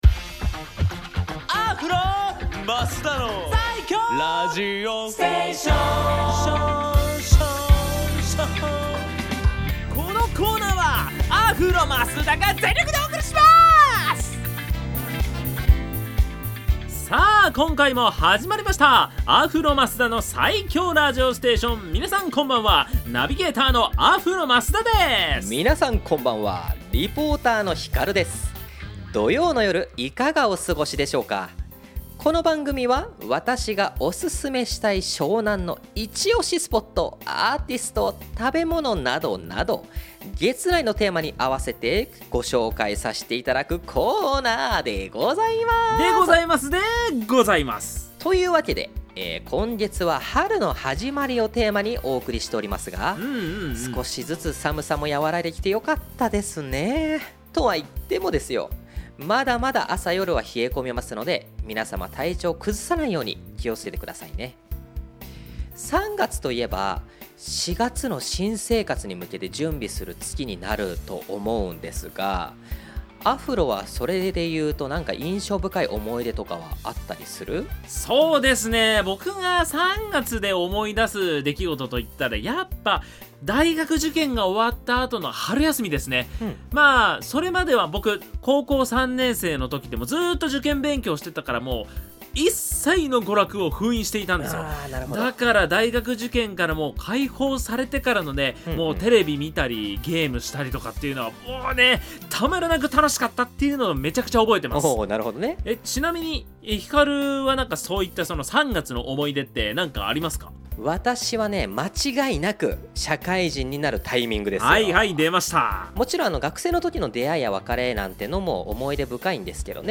放送音源はこちら
こちらの放送音源をお届けします♪